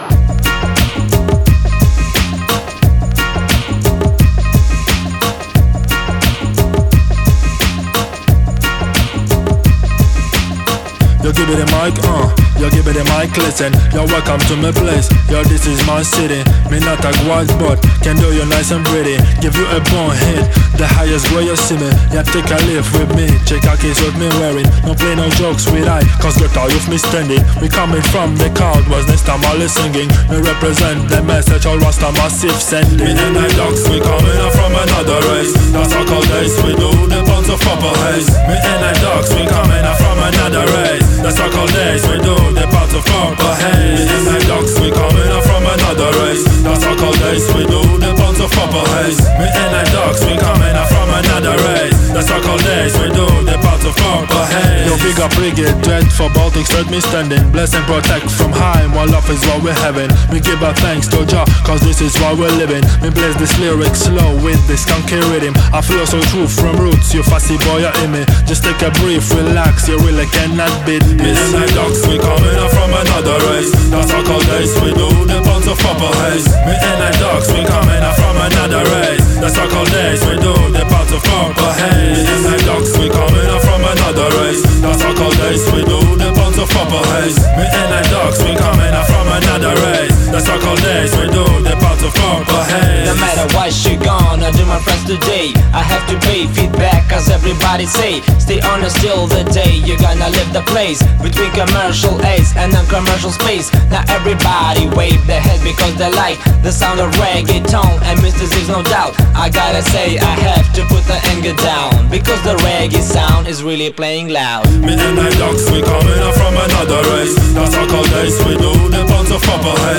dubplate